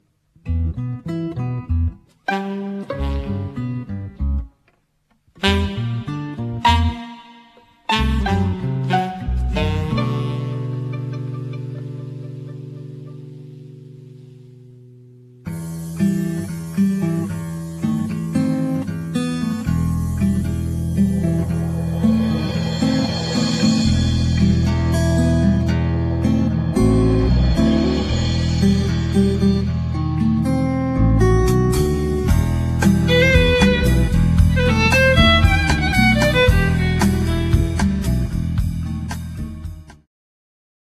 Etno, jazz, rock i coś jeszcze.